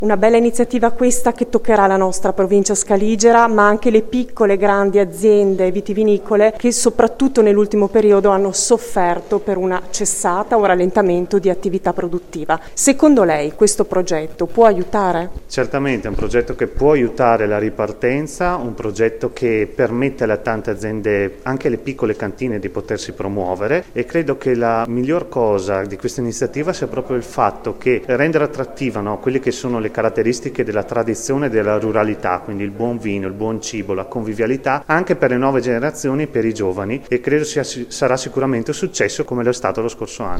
ha raccolto per noi le dichiarazioni dei presenti, per capire meglio lo spirito dell’evento.